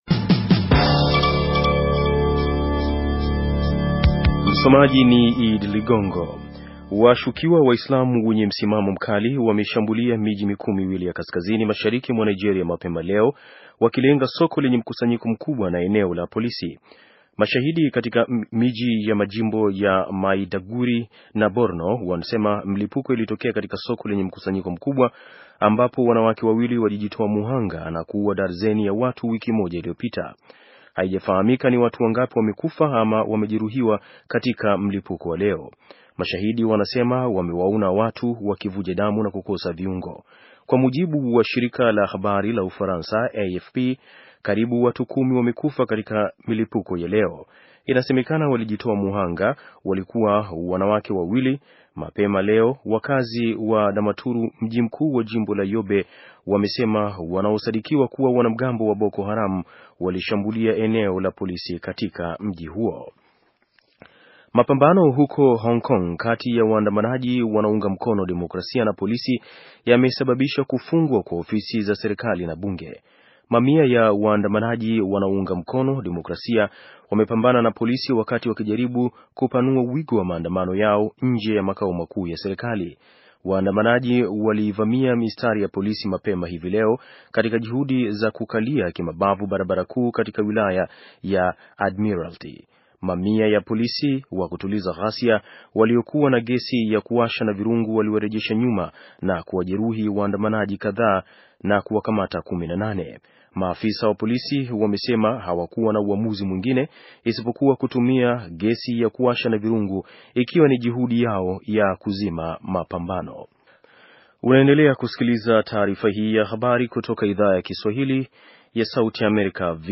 Taarifa ya habari - 6:35